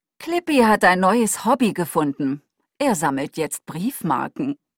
Im Folgenden noch drei Audio-Proben von der deutschen Cortana-Stimme. Wenn die wirklich echt ist und alle Sätze so klingen, ist die Stimme sogar etwas natürlicher als das Pendant von Apple.